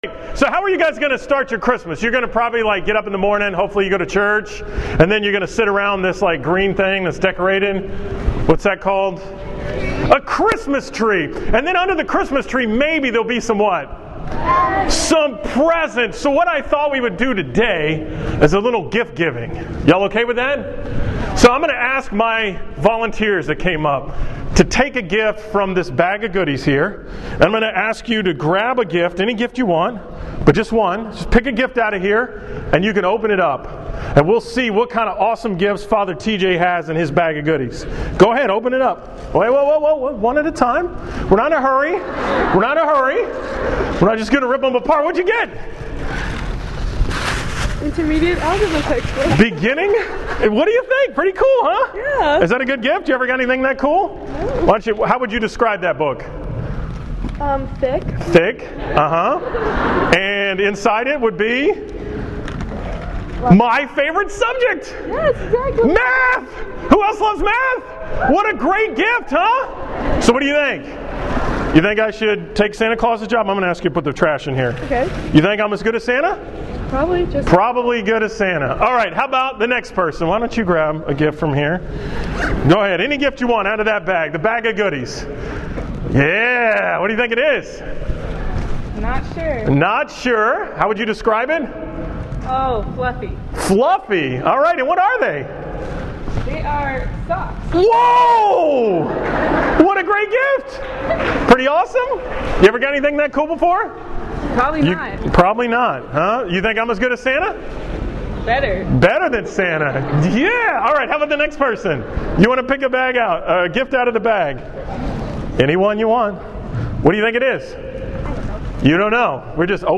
From the all school Mass at Duchesne on Friday, December 18, 2015